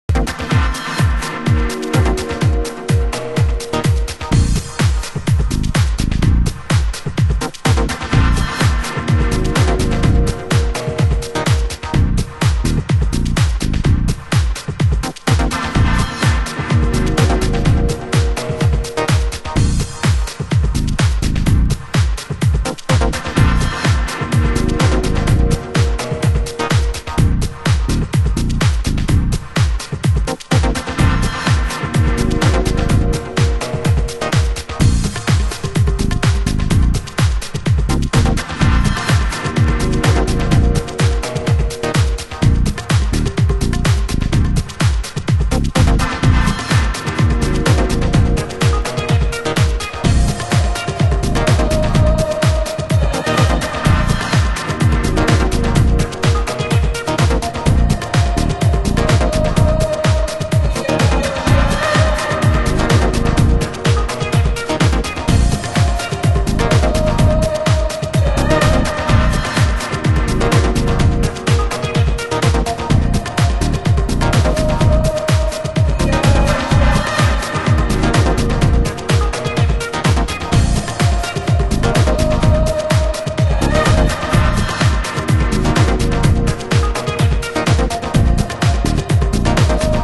HOUSE MUSIC